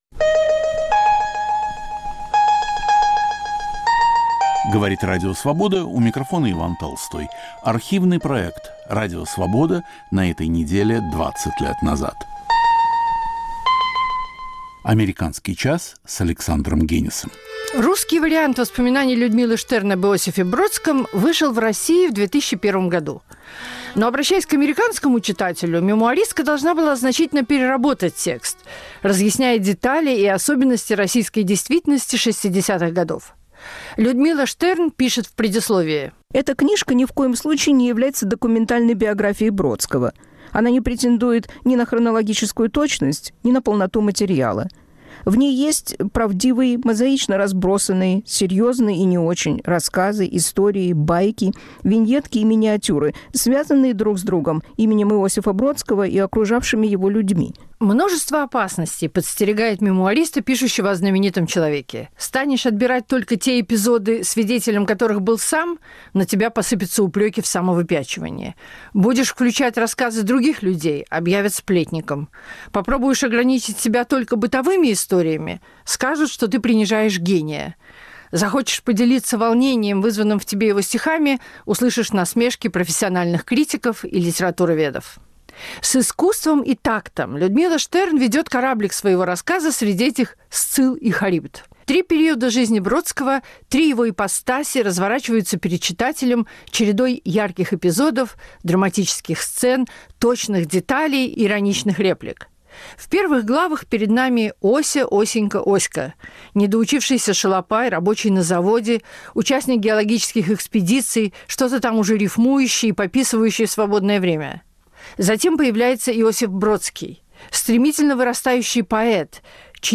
Ведет Александр Генис.